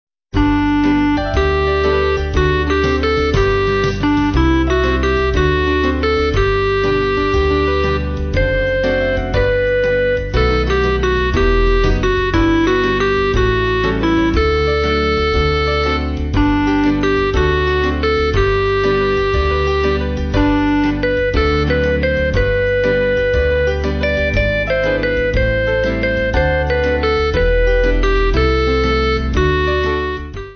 Kid`s club music